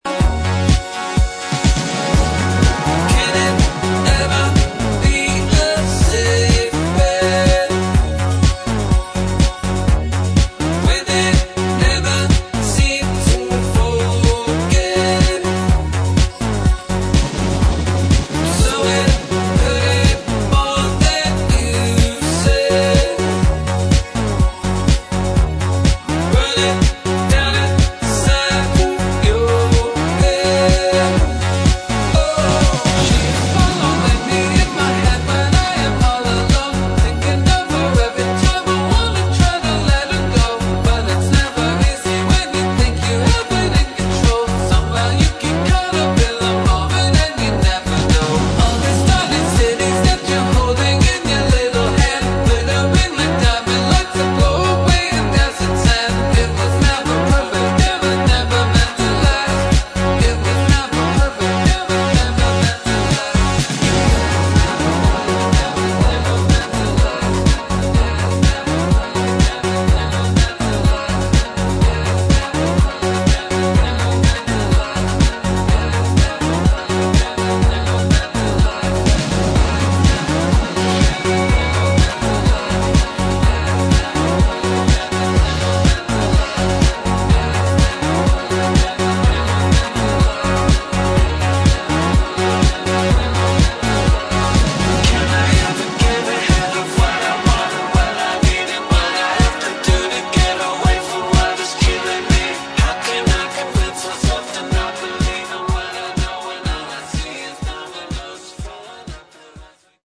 [ POP HOUSE / BASS / TECH HOUSE ]